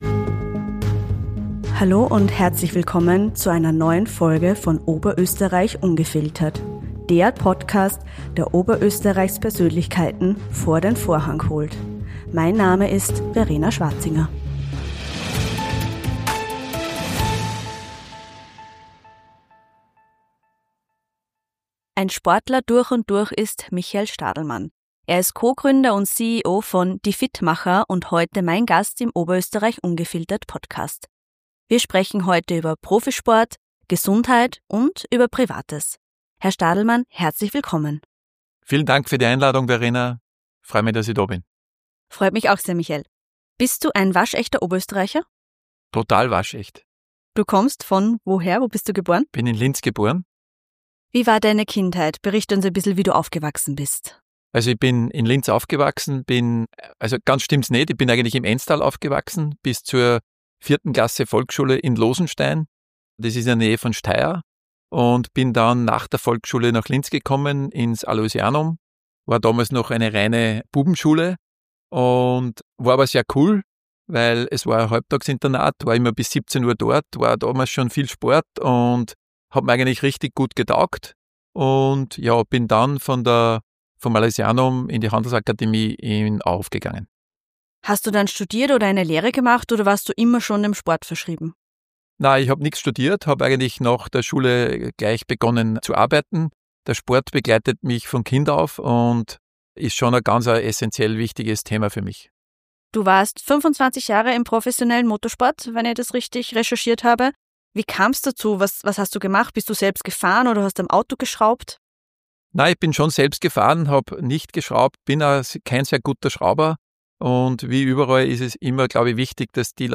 Im Gespräch werden Vorurteile über Sport und Fitness entkräftet: Sport allein macht nicht automatisch gesund, sondern kann falsch angewandt auch schaden. Es braucht eine fundierte und regelmäßige Analyse, zum Beispiel des Blutes oder Darmes, um dem eigenen Körper wirklich Gutes zu tun.